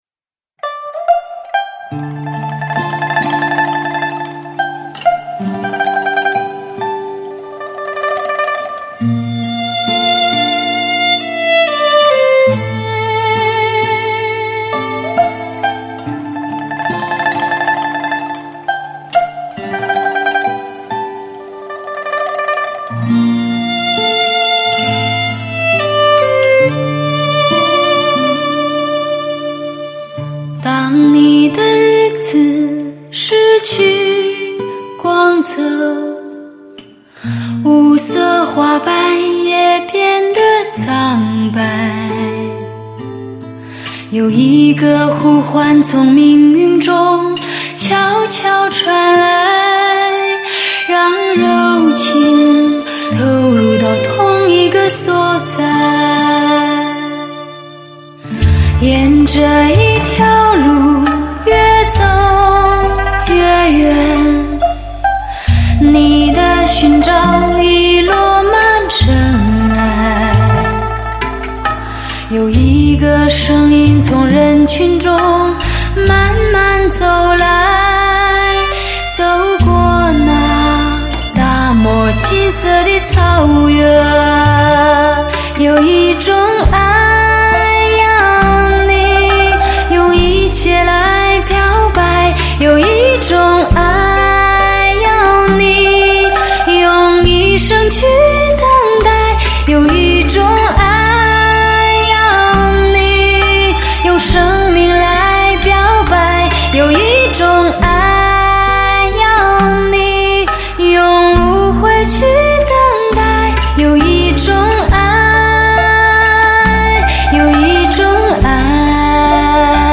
为低音质MP3